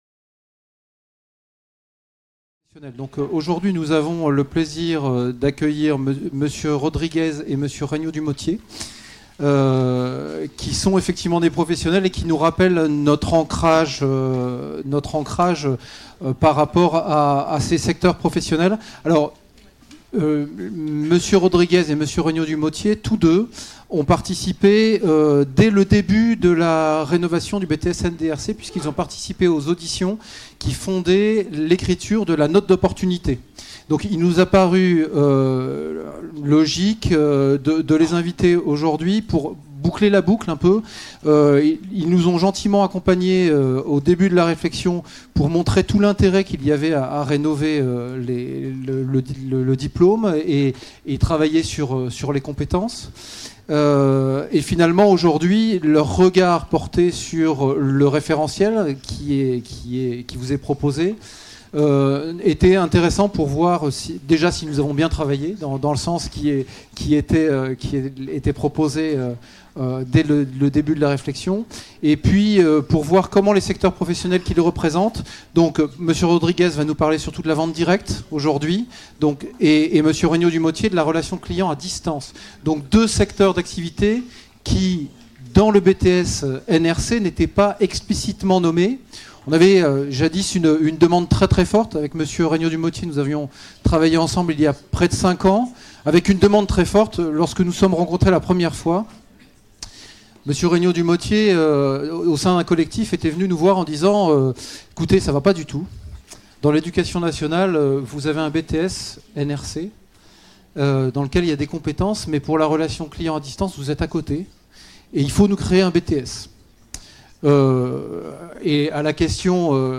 Mise en œuvre du BTS NDRC : 5 - Table ronde de professionnels | Canal U